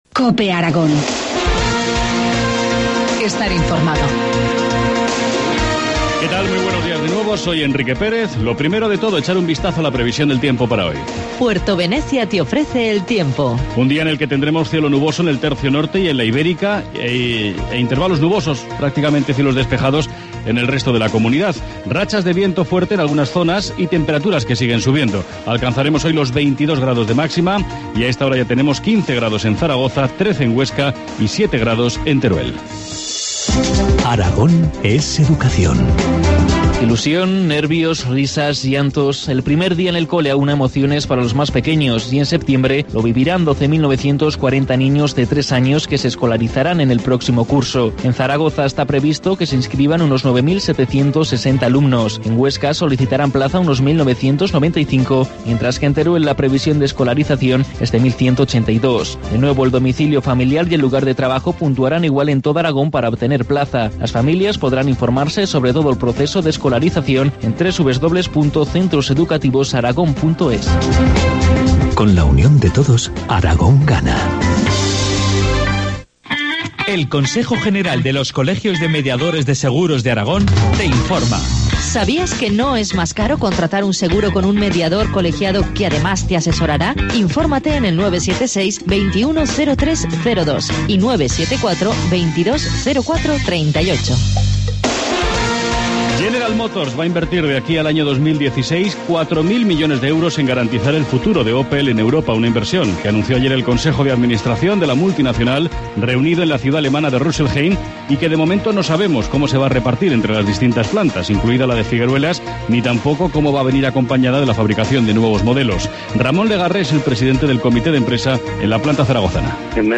Informativo matinal, jueves 11 de abril, 8.25 horas